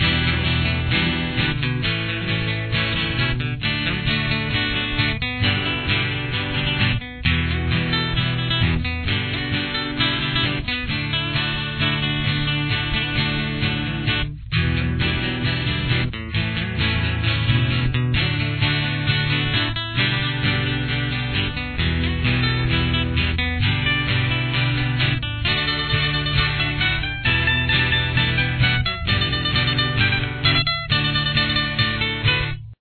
• Key Of: D
• Solo: D Pentatonic Major (with a few alternates)